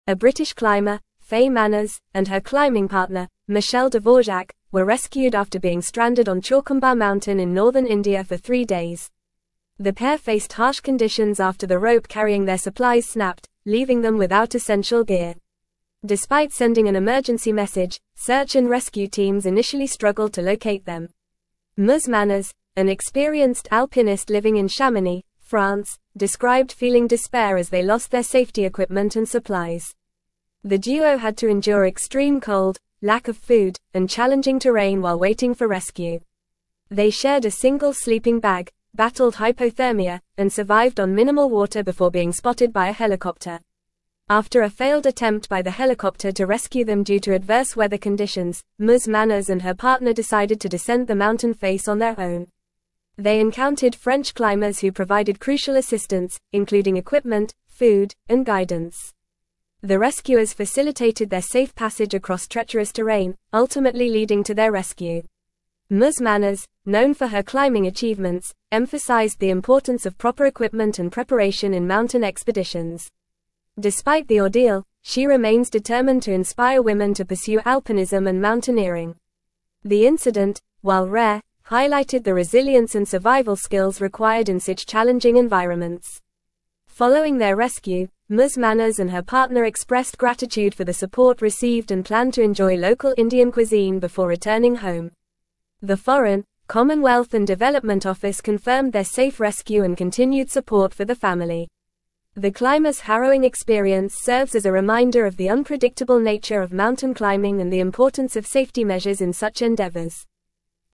Fast
English-Newsroom-Advanced-FAST-Reading-Resilience-and-Survival-Climbers-Ordeal-on-Himalayan-Mountain.mp3